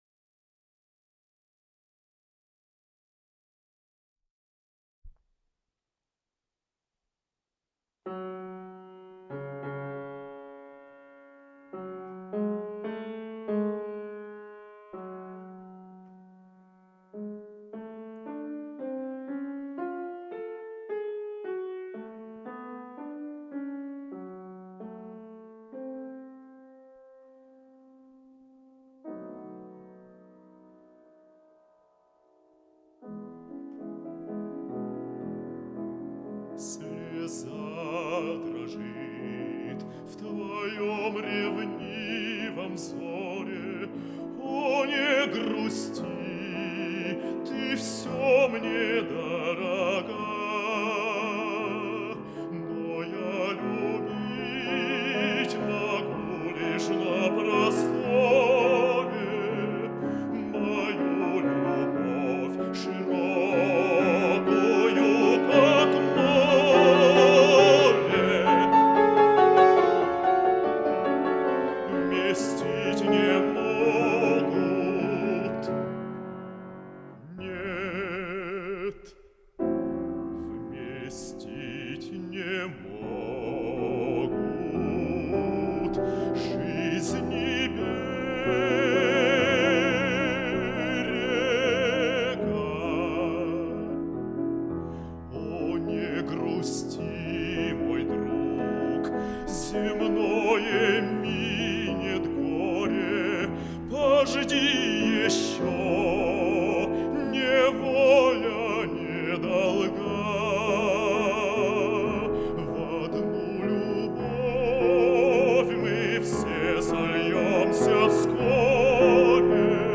АВМ-008 — Произведения для баритона и фортепиано — Ретро-архив Аудио